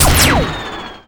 pshoot1.wav